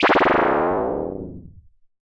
gravitychange.wav